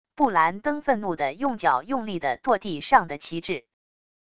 布兰登愤怒的用脚用力的跺地上的旗帜wav音频